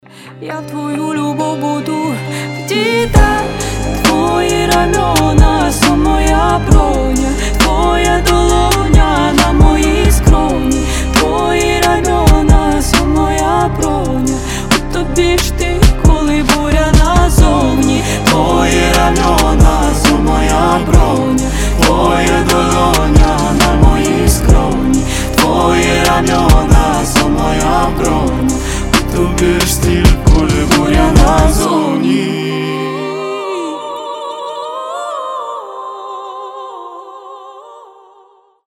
• Качество: 320, Stereo
дуэт
нежные